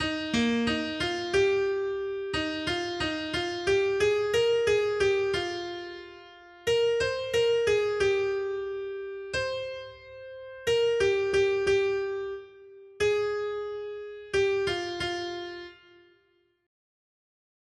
Noty Štítky, zpěvníky ol428.pdf responsoriální žalm Žaltář (Olejník) 428 Skrýt akordy R: Pane, tys nám býval útočištěm od pokolení do pokolení. 1.